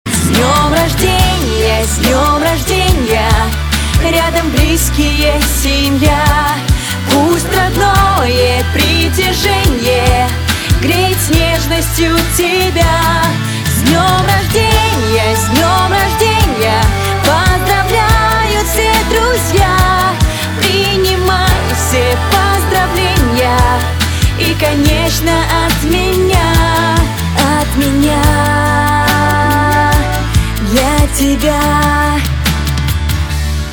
• Качество: 320, Stereo
праздничные